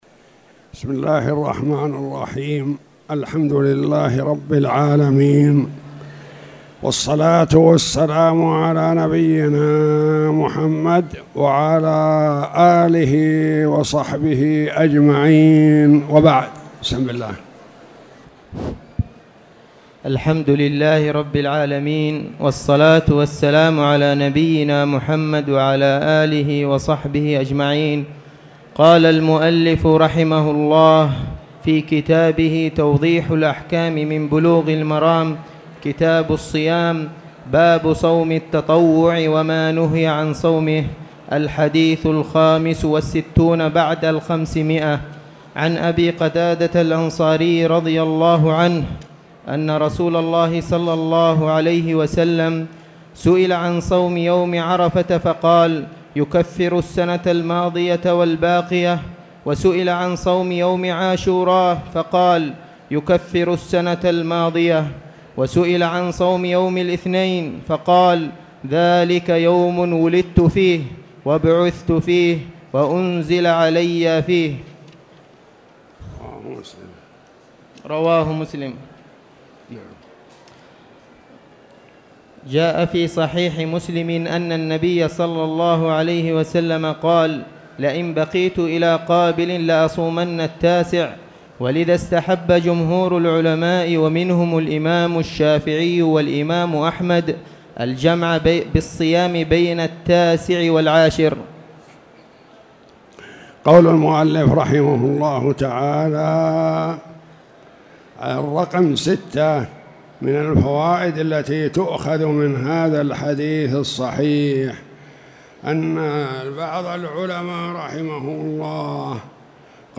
تاريخ النشر ١١ شوال ١٤٣٧ هـ المكان: المسجد الحرام الشيخ